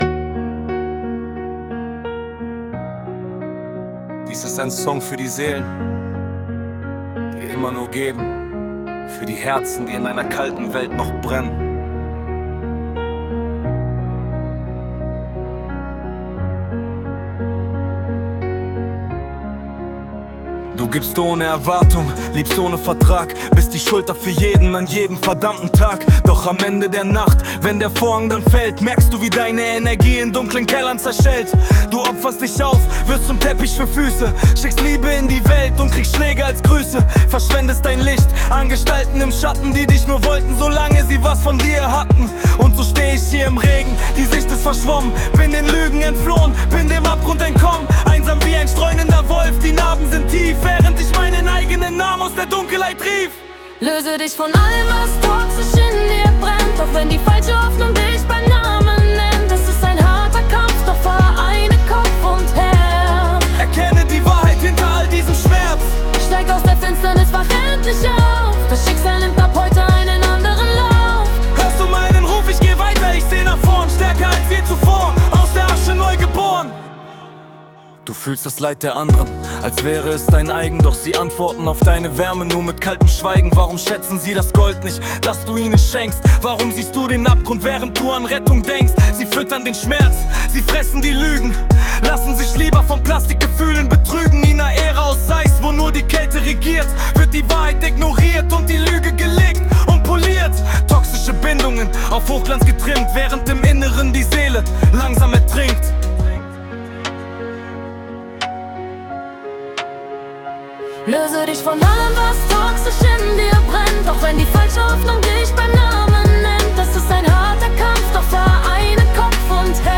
Melodic Rap Version, MaleFemale